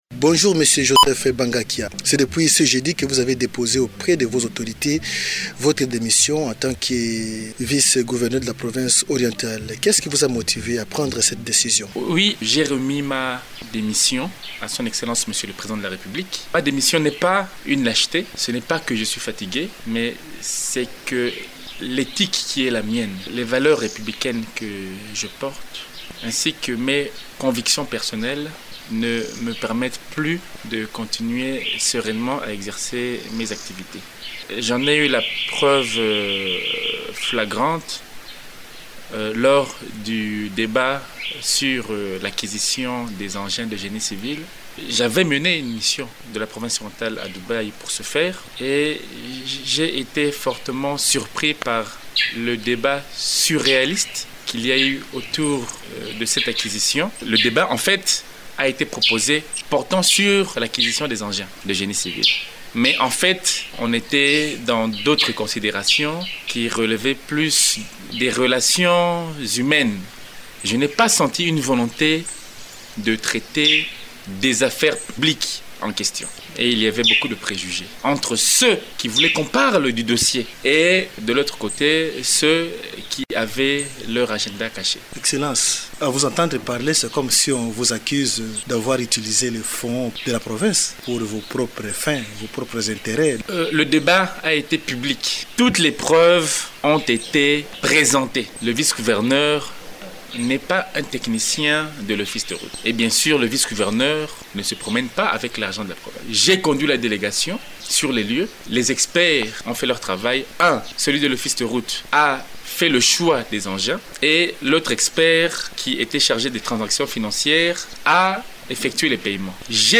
Qu’est-ce qui a poussé le vice-gouverneur à démissioner ?